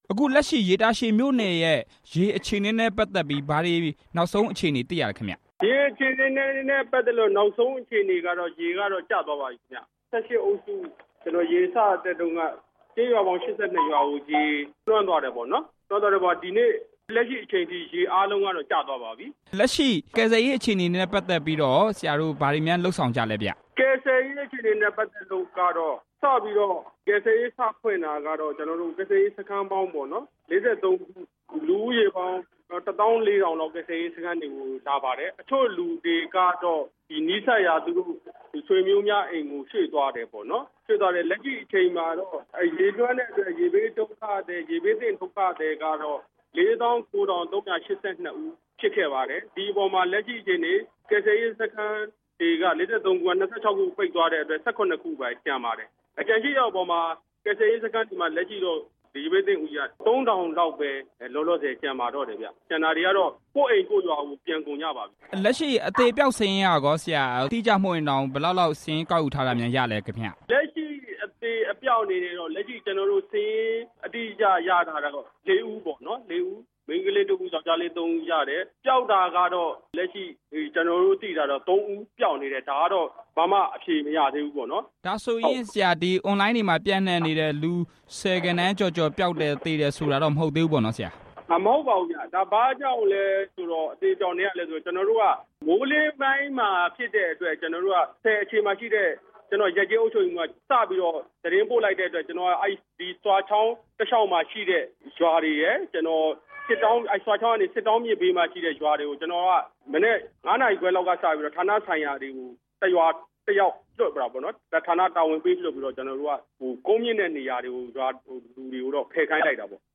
ဆွာရေဘေးအခြေအနေ ဆက်သွယ်မေးမြန်းချက်